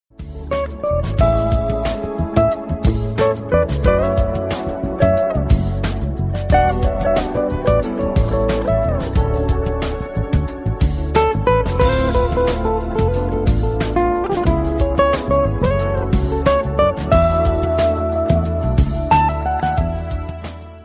loving-jazz_23905.mp3